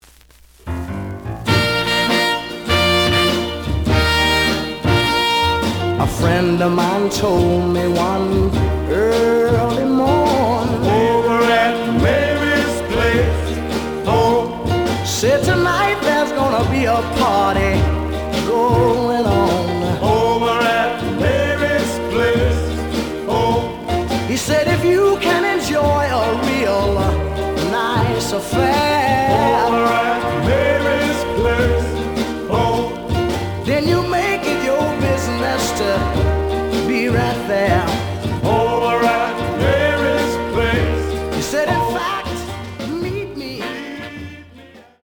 The audio sample is recorded from the actual item.
●Genre: Soul, 60's Soul
Slight noise on beginning of both sides, but almost good.)